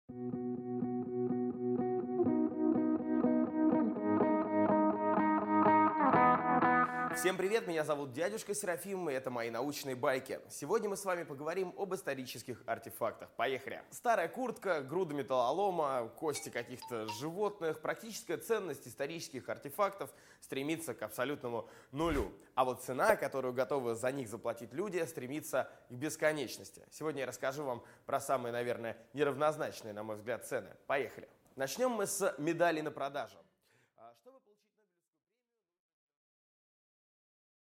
Аудиокнига Научные артефакты | Библиотека аудиокниг
Прослушать и бесплатно скачать фрагмент аудиокниги